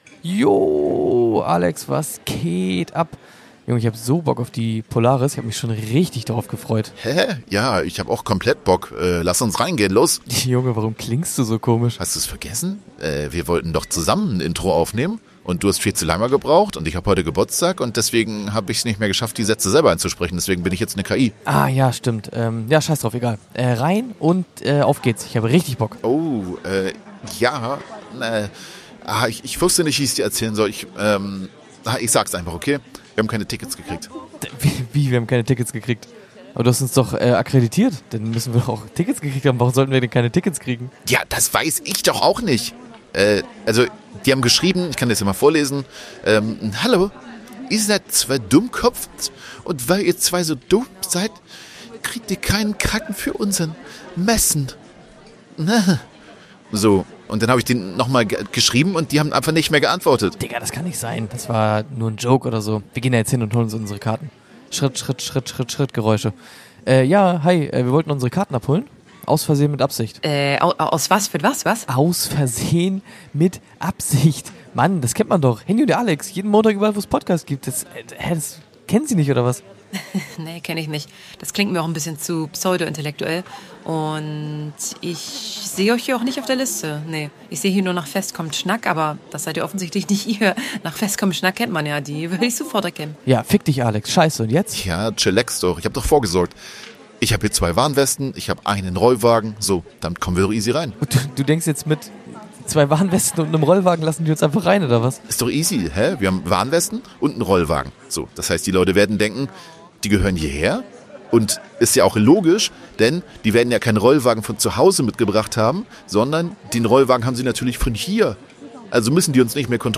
AVMA auf der POPOlaris 2025 [Spezialfolge]